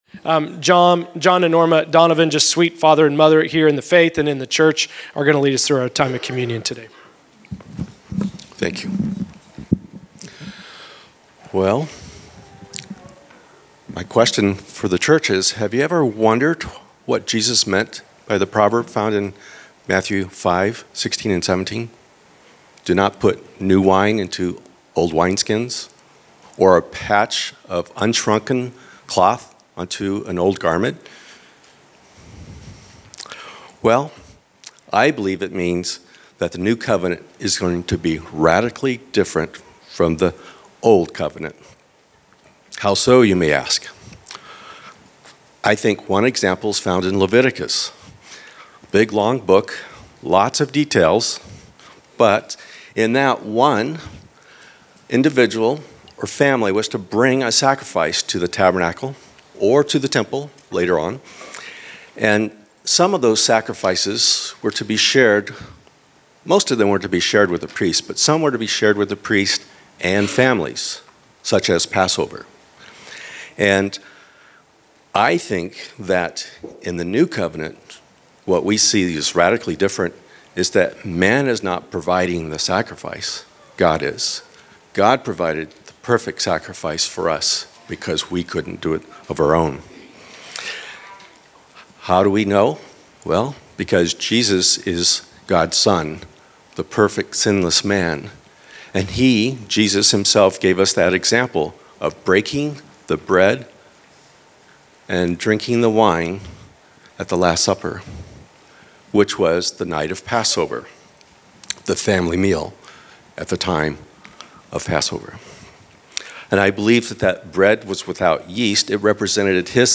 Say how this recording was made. Location: El Dorado